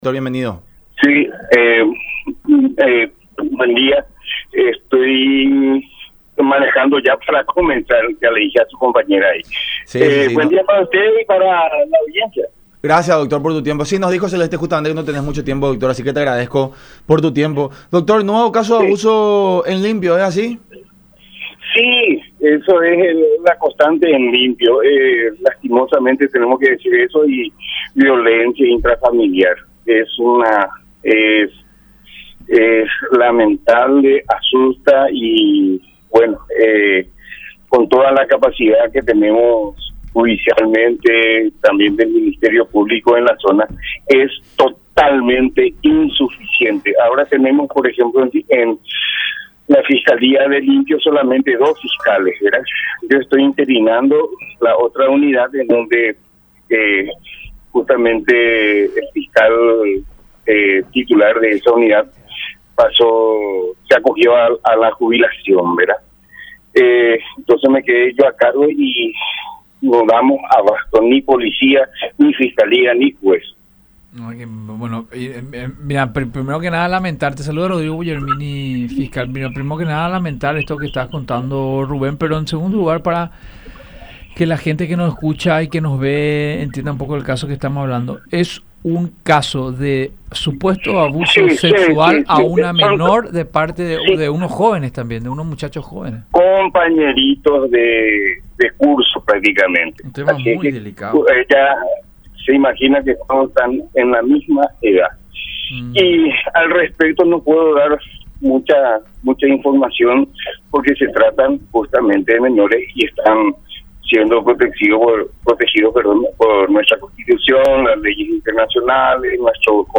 El juez interino, Rubén Moreno, no brindó más detalles, ya que son menores, todos los implicados.
“Compañeros de curso, al respecto no puedo dar mucha información, porque son menores y están siendo protegidos” , agregó en el programa “La Unión Hace La Fuerza” por radio La Unión y Unión Tv.